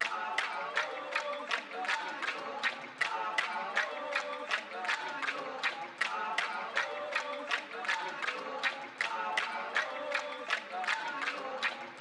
African Chant.wav